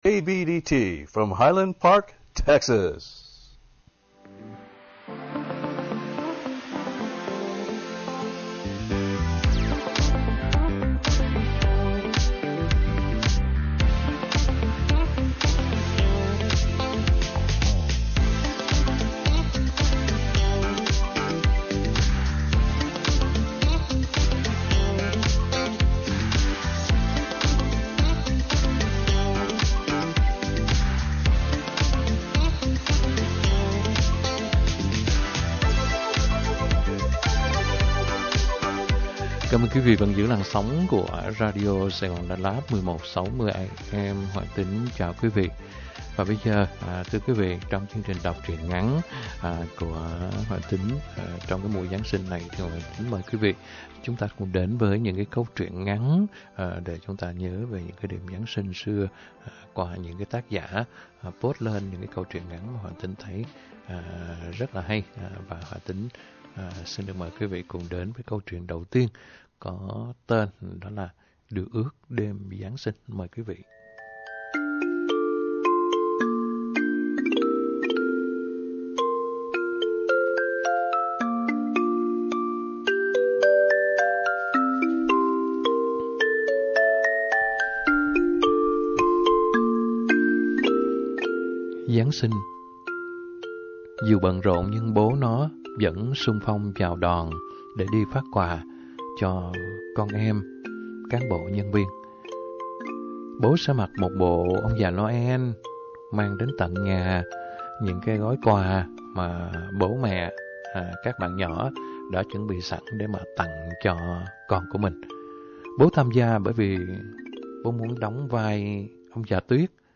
Đọc Truyện Ngắn = Điều Ước Đêm Giáng Sinh - 12/13/2022 .